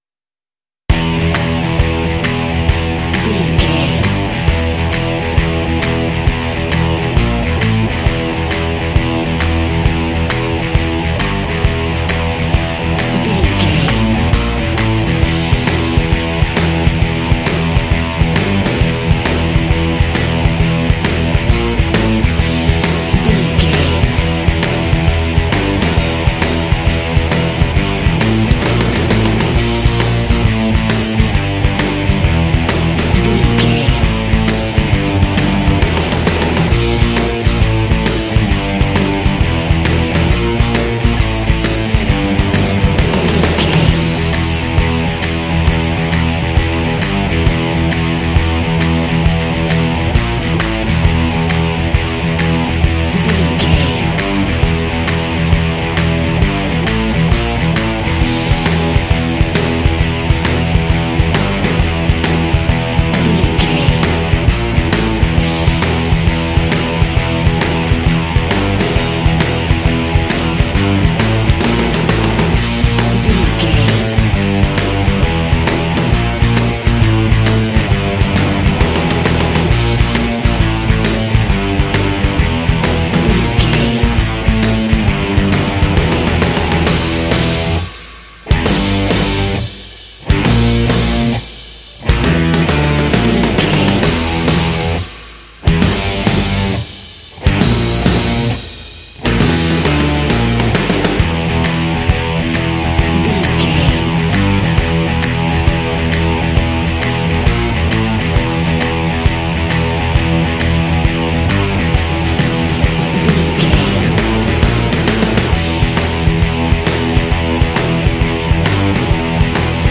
Aeolian/Minor
driving
funky
energetic
bouncy
bass guitar
electric guitar
drums
synth- pop
alternative rock